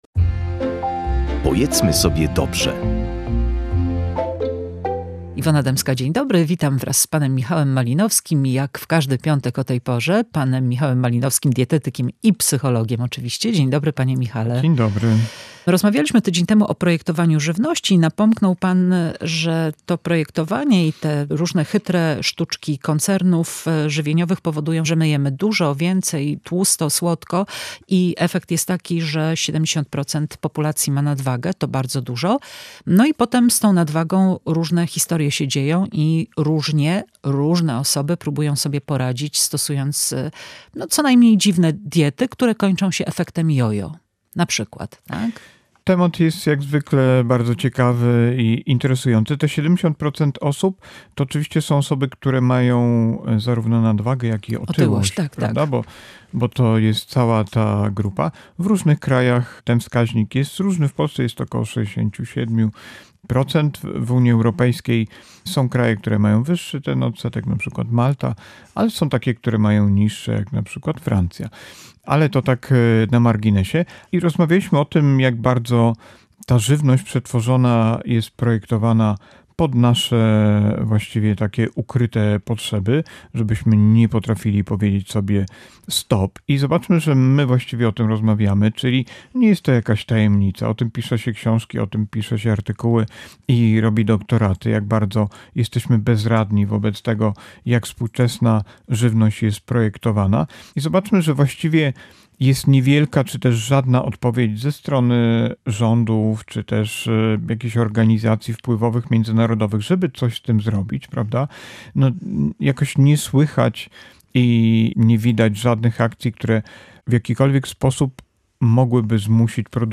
Dlaczego tak się dzieje? O tym w rozmowie